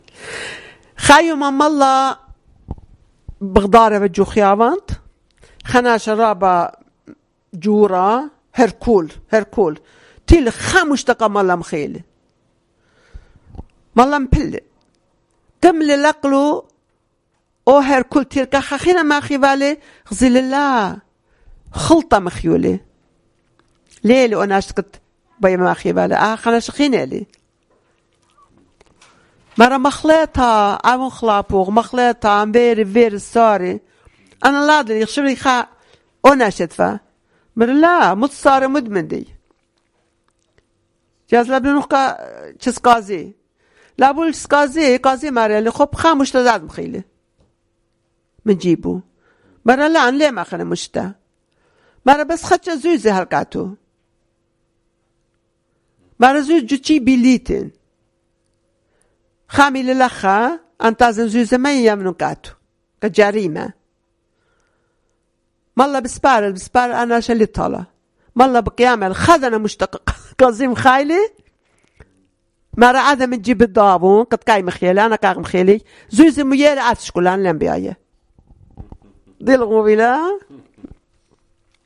Urmi, Christian: Mistaken Identity